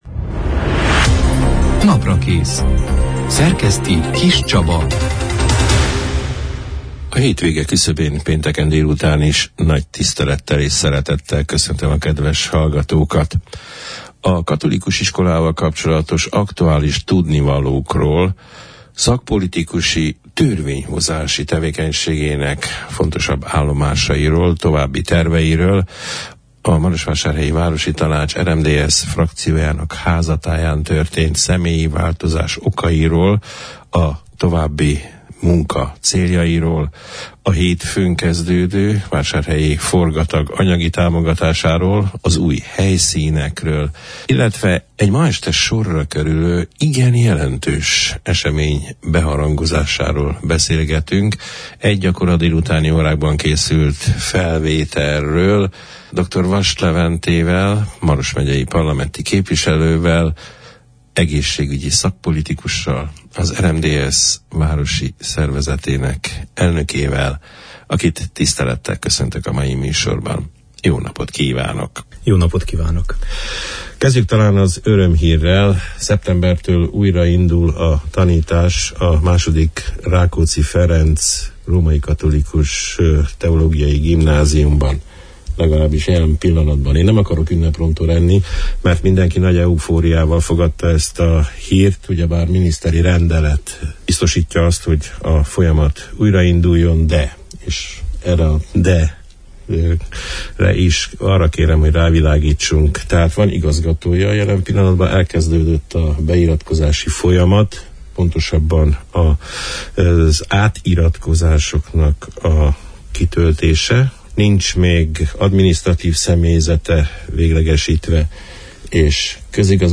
A római katolikus gimnáziumban újrainduló tanítással kapcsolatos aktualitásokról, szakpolitikusi, az egészségüggyel kapcsolatos megvalósításokról, a közelgő parlamenti űlésszak feladatairól, a városi tanács tevékenységével kapcsolatos információkról, az új tagra háruló feladatokról, a hétfőn kezdődő VI. Vásárhelyi Forgatag új helyszíneiről, a polgármesteri hivatal által nyújtott anyagi támogatásról, a Borudvar sajátos programjairól beszélgettünk az augusztus 24 – én, pénteken elhangzott Naprakész műsorban Dr. Vass Levente parlamenti képviselővel, egészségügyi szakpolitikussal, az RMDSZ marosvásárhelyi szervezetének elnökével.